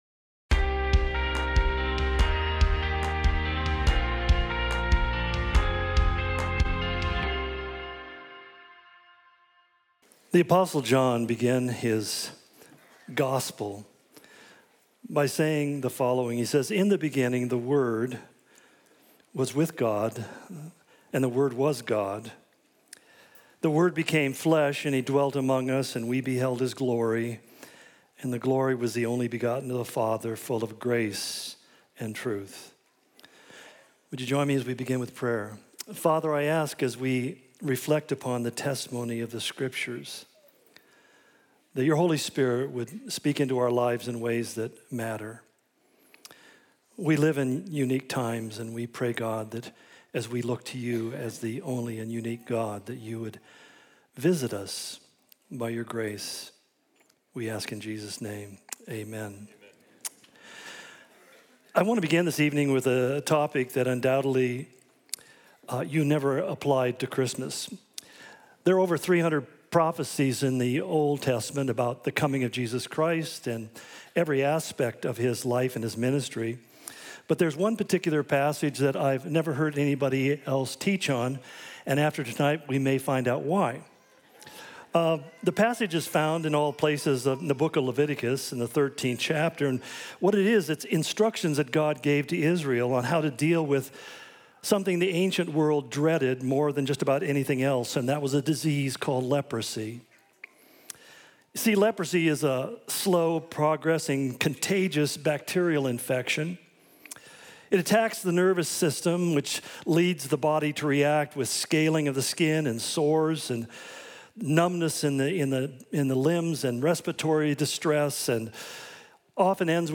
Christmas Eve 2024 - Candlelight Service